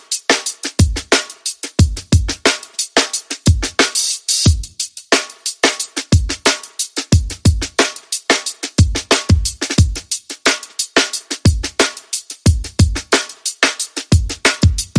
Free Music sound effect: Bass Drop.
Bass Drop
# bass # drop # edm About this sound Bass Drop is a free music sound effect available for download in MP3 format.
057_bass_drop.mp3